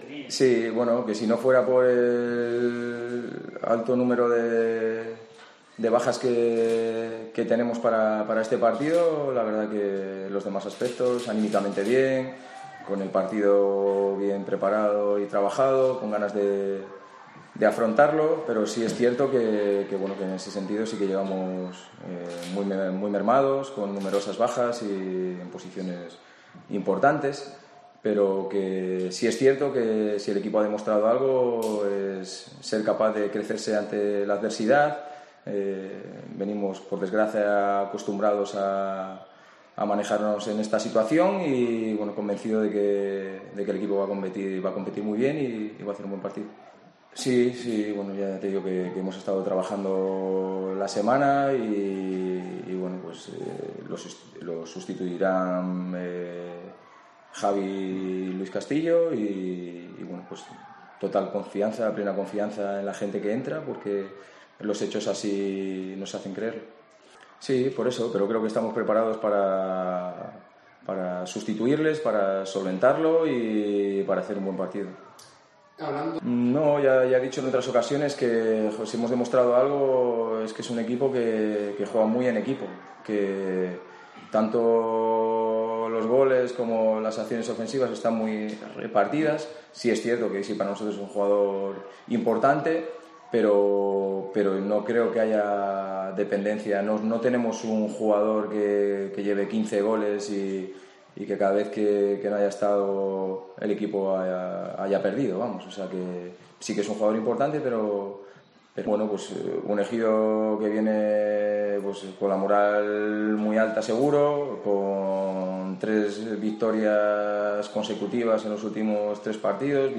El técnico del Universidad Católica de Murcia Club de Fútbol ha comparecido estes viernes en  El Mayayo para analizar el partido de este domingo (12h) frente al CD El Ejido 2012 en La Condomina.